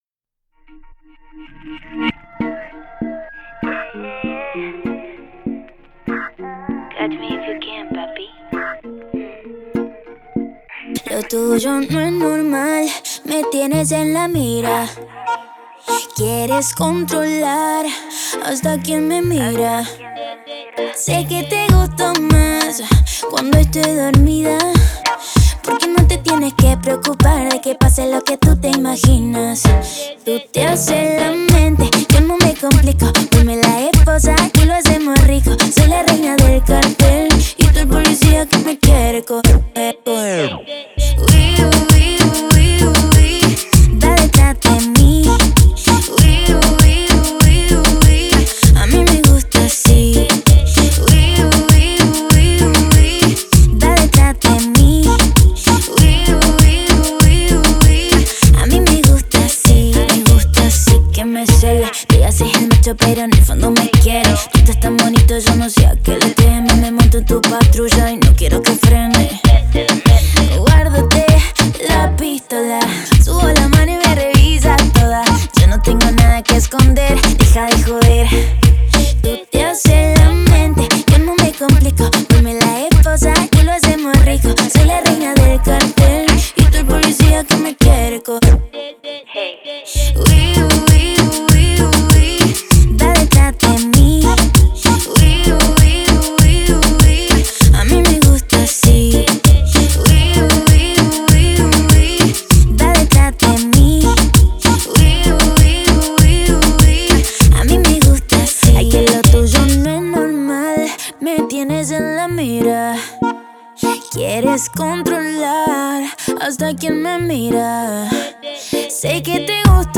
это атмосферная композиция в жанре инди-электро-поп